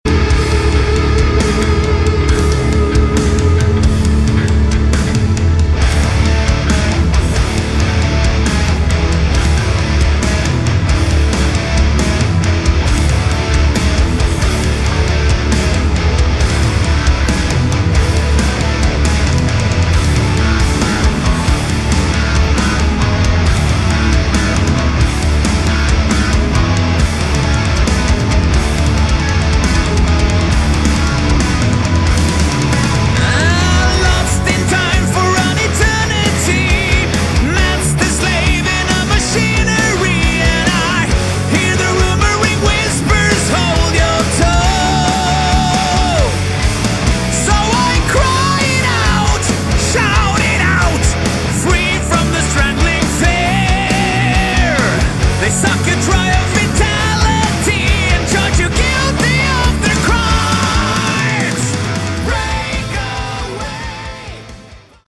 Category: Hard Rock
vocals
rhythm, lead, and acoustic guitars
bass
drums, percussion